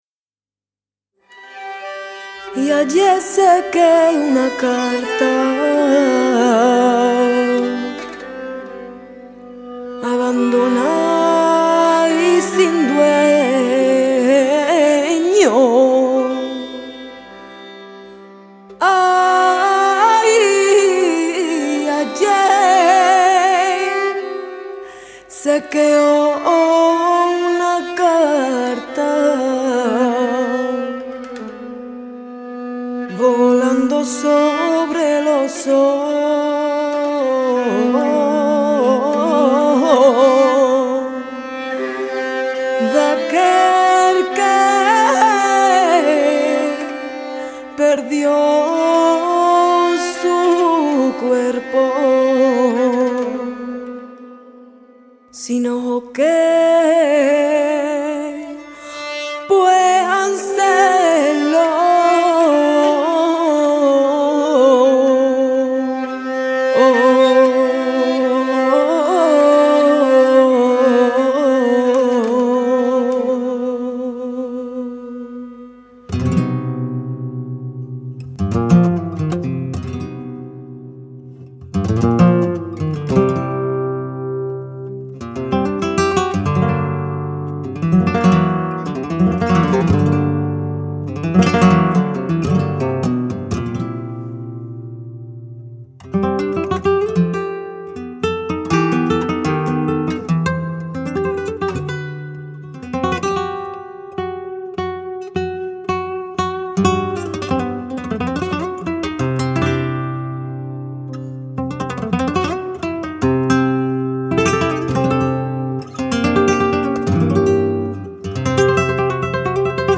chant
guitare
nickelharpa